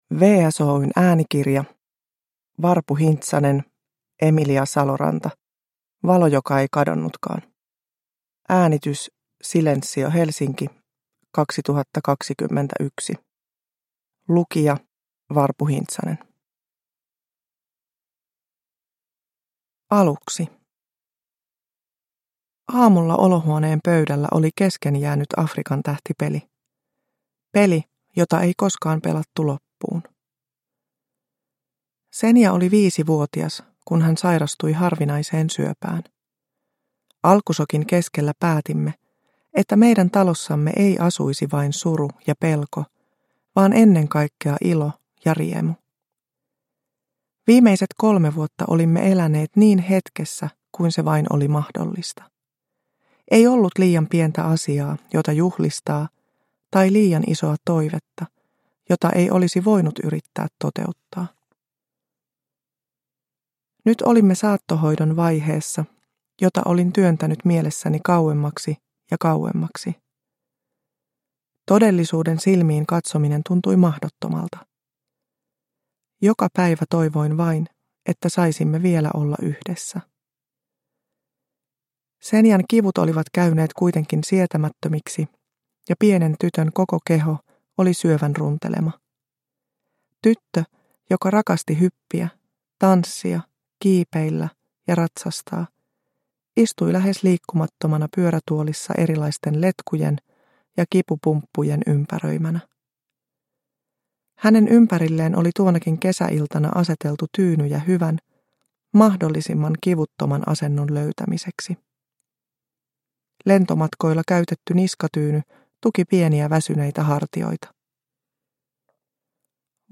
Valo joka ei kadonnutkaan – Ljudbok – Laddas ner